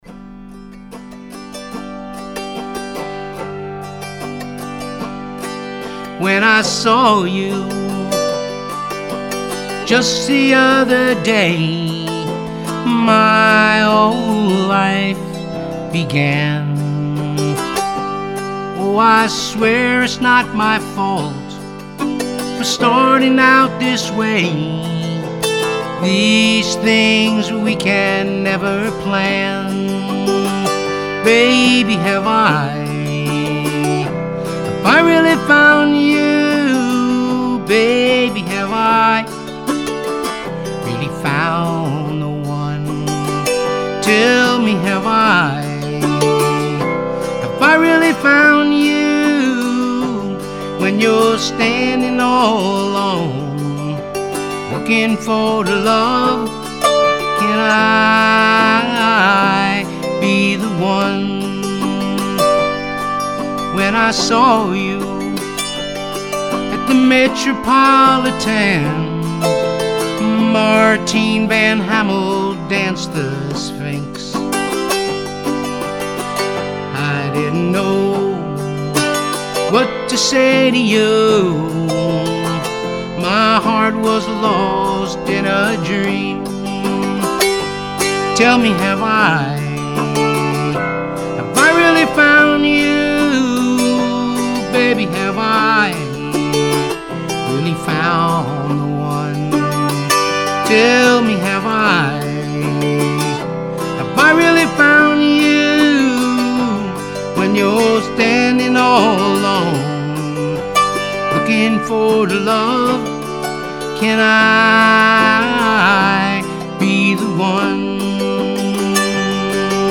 Recorded at Clinton Studios New York City